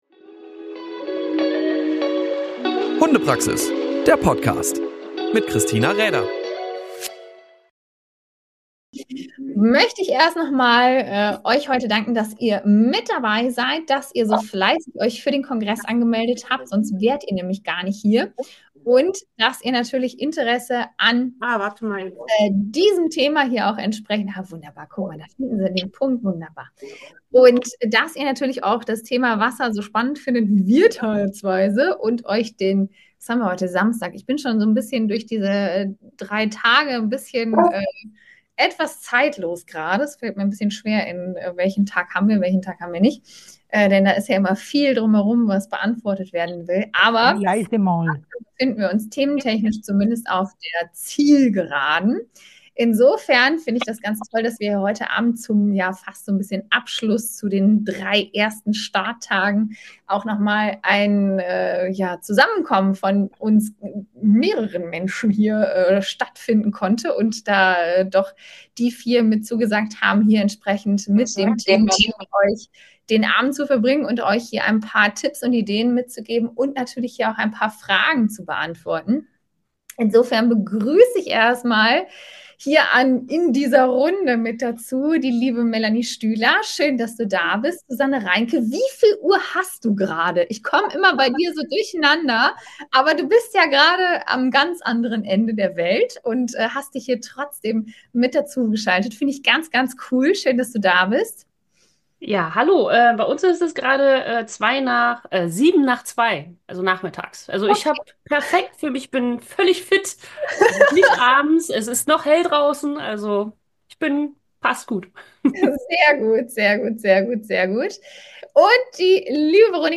Beschreibung vor 1 Jahr Einer der live-Abende, welche in den Öffnungstagen des Dummytraining-Onlinekongresses stattgefunden haben.
Unsere wichtigsten Do´s and Dont´s findest du zum Thema Wasserarbeit in dieser Episode. Hier findest du unseren Gesprächsteil des Abends!